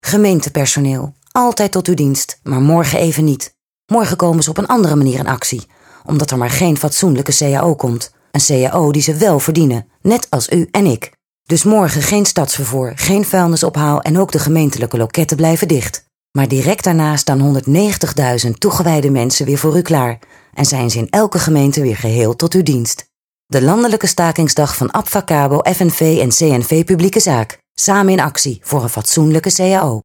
Speaker olandese.  Dutch voice over